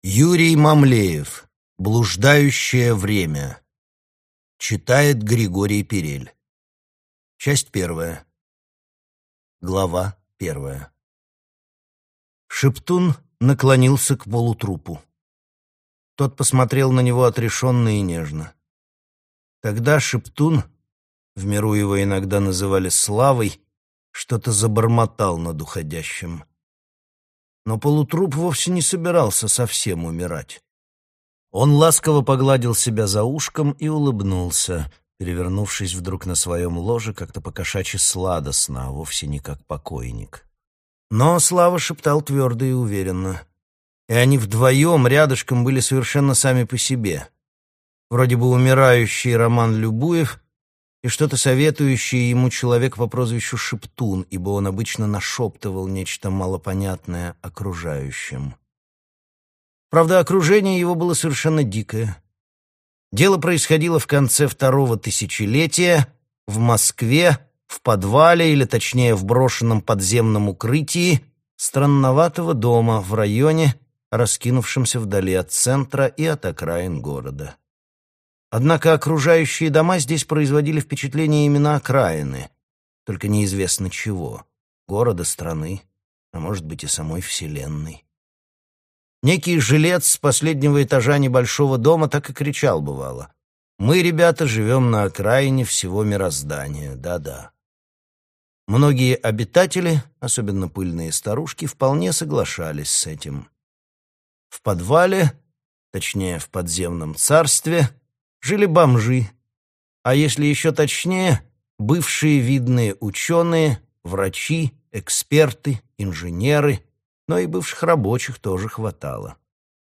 Аудиокнига Блуждающее время | Библиотека аудиокниг